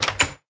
door_open.ogg